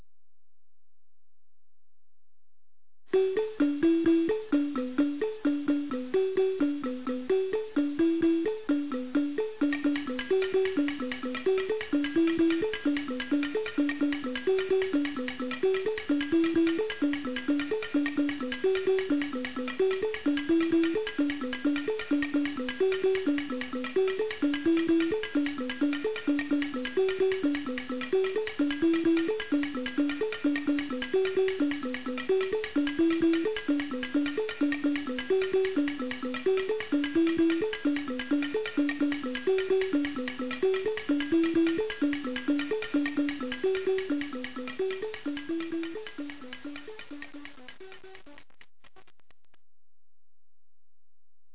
AMADINDA - das Xylophon aus Buganda
Die folgenden Klangbeispiele (Computersimulationen) zeigen am Lied Ssematimba ne Kikwabanga (Ssematimba und Kikwabanga), wodurch die Wahrnehmung der zweitönigen (und der dreitönigen) Melodie unterstützt oder gestört werden kann.